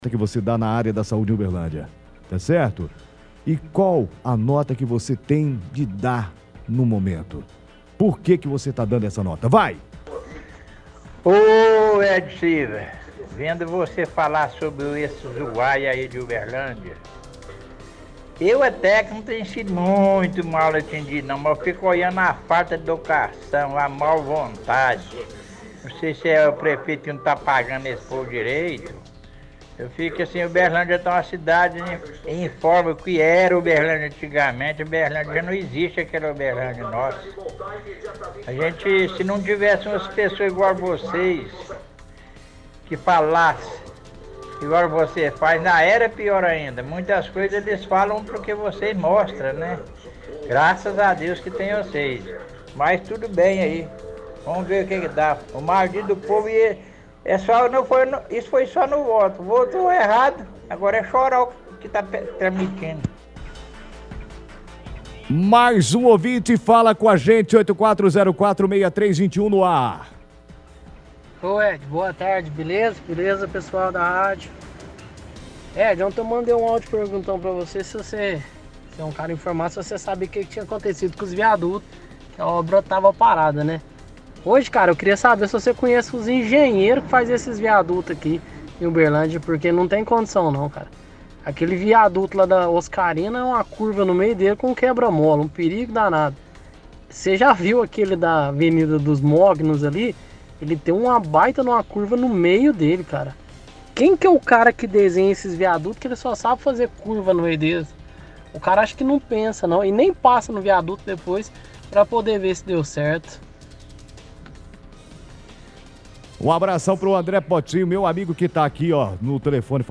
Ligação Ouvintes